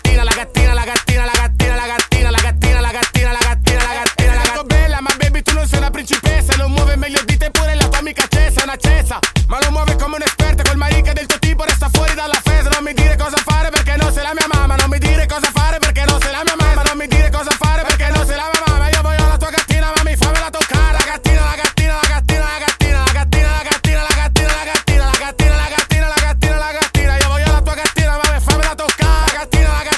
Жанр: Рэп и хип-хоп / Альтернатива
# Alternative Rap